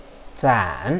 zan3.wav